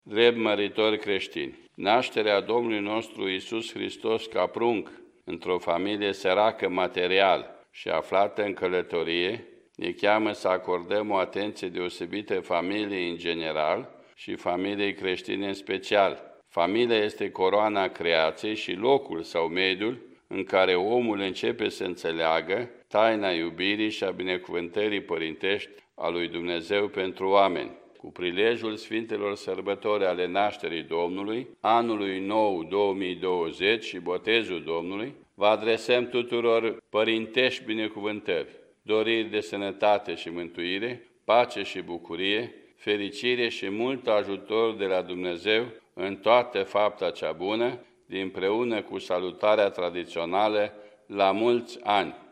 În Pastorala de Crăciun, Patriarhul României arată că familia se confruntă, adeseori, în societatea contemporană, cu o serie de provocări şi crize şi cheamă la ajutorarea bolnavilor, a bătrânilor şi a săracilor: